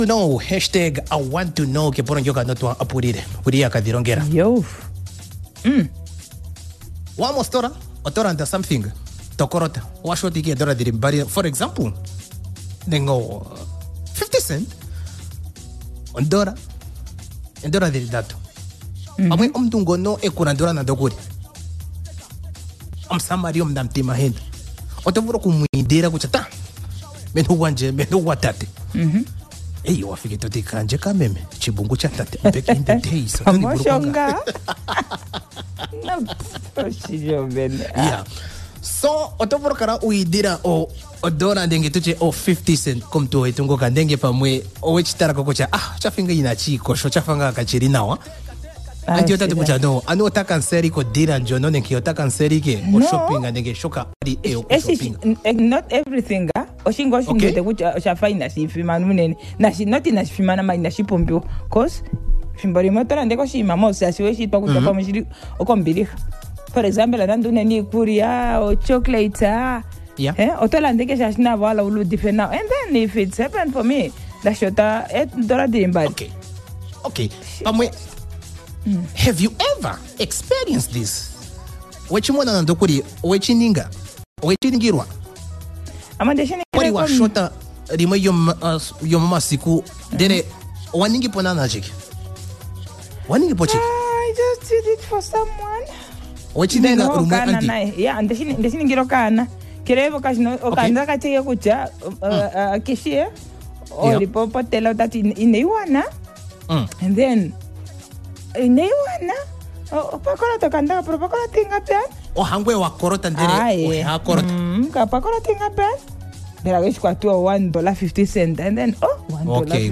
21 Nov Conversation," Easy Drive